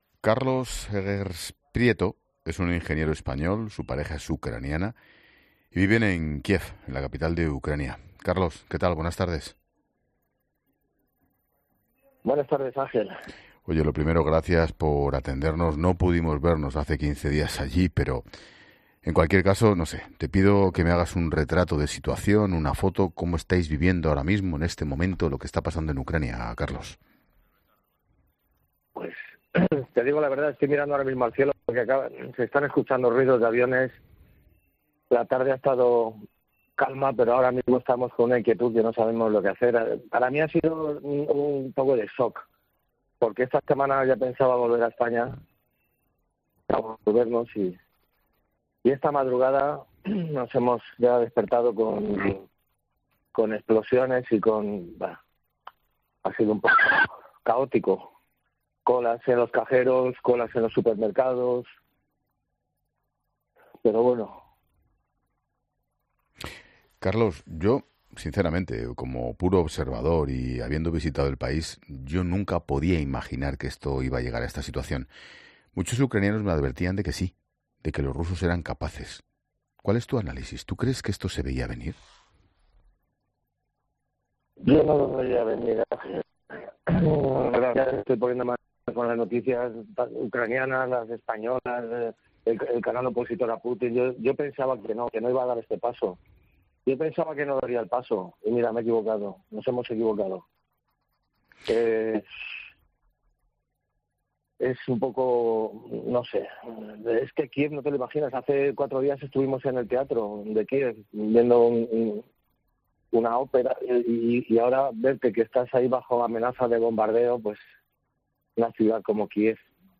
Ángel Expósito entrevista a un ingeniero español que no termina de creerse la situación límite que está viviendo